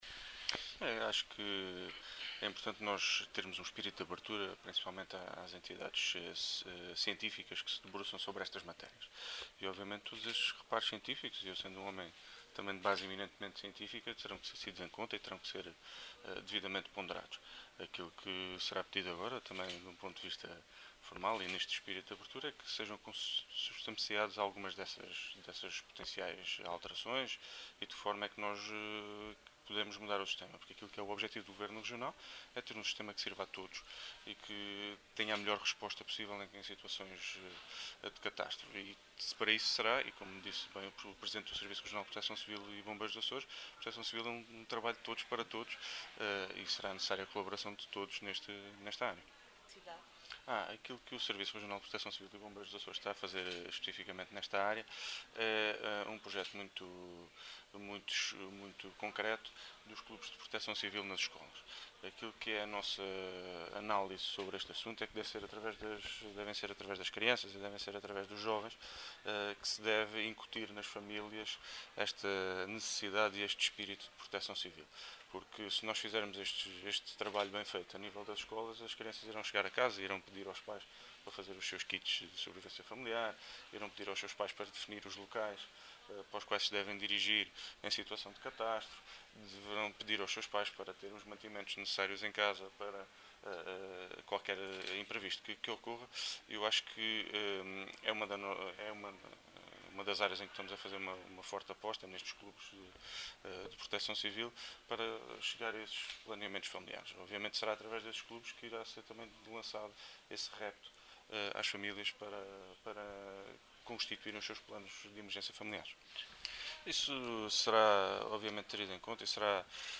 O Secretário Regional, que falava aos jornalistas à margem da cerimónia que assinalou o 20.º aniversário do Comando Operacional dos Açores, revelou que o Serviço Regional de Proteção Civil e Bombeiros dos Açores está a elaborar um “projeto muito concreto, que se baseia na criação dos Clubes de Proteção Civil nas escolas”.